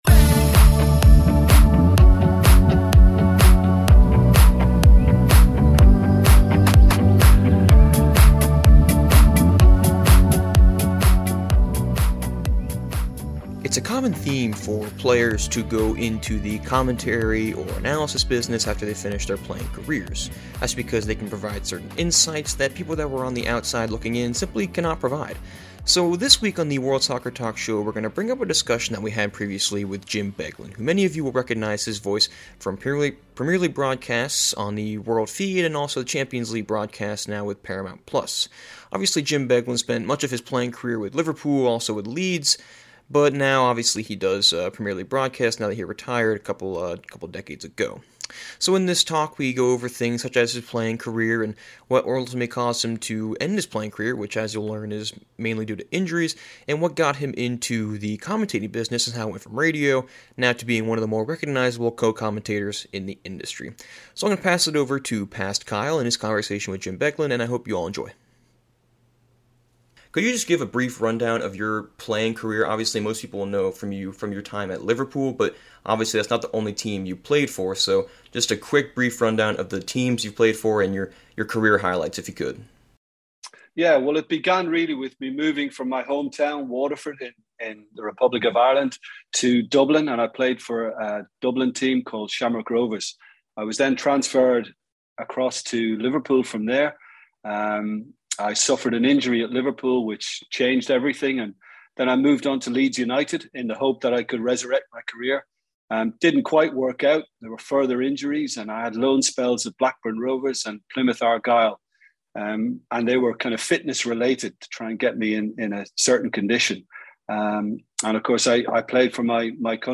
Jim Beglin interview: How he became a co-commentator